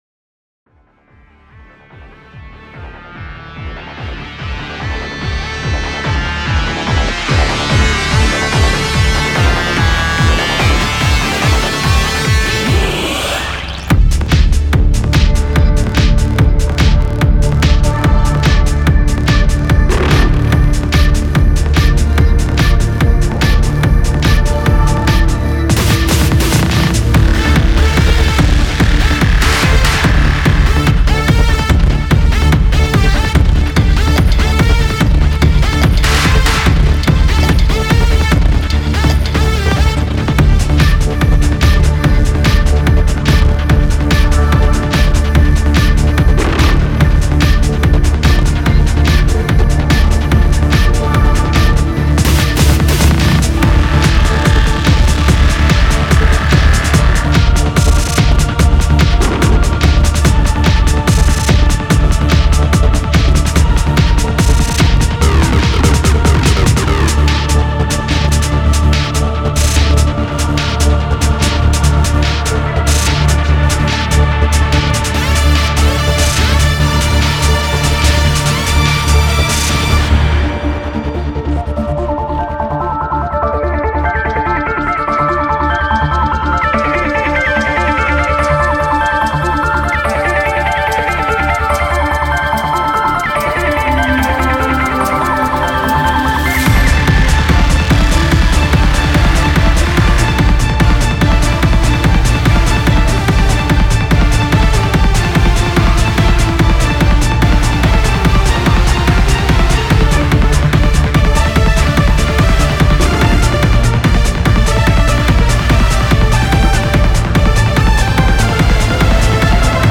Impact is a powerhouse collection of 100 cinematic EDM & sci-fi kick presets for Reveal Sound’s Spire. Designed to hit hard, resonate deep, and drive your productions with explosive energy.
From futuristic punch to earthshaking lows, Impact delivers a versatile set of kick sounds built for trailers, electronic music, sound design, and cinematic scoring.
• Heavy low end & clarity: each kick is sculpted to balance power and intelligibility.
• Intended to deliver impact (literally) — from subtle, tight hits to massive, cinematic stomps.
• * The video and audio demos contain presets played from Impact sound bank, every single sound is created from scratch with Spire.